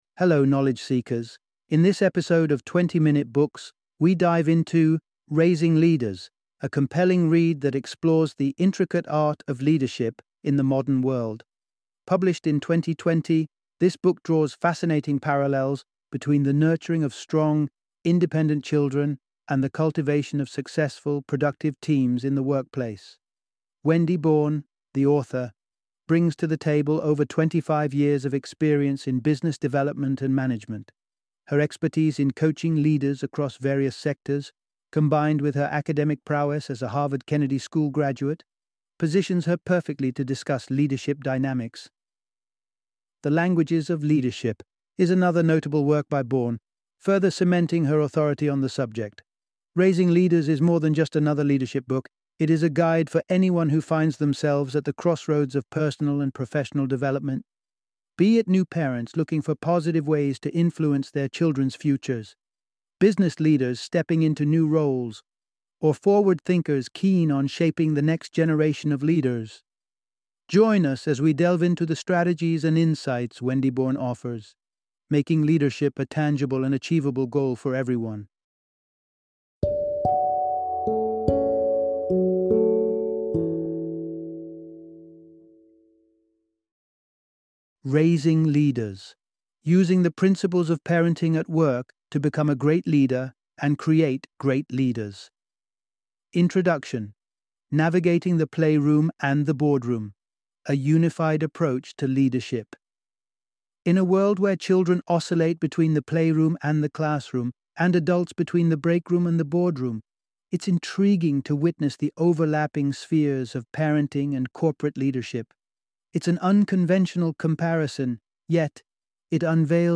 Raising Leaders - Audiobook Summary